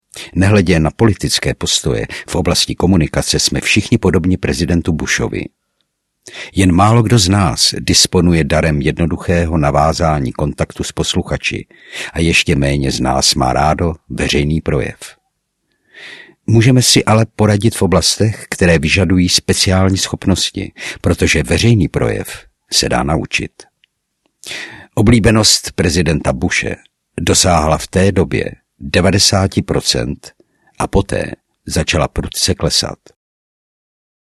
Mluvte jako profesionál audiokniha
Ukázka z knihy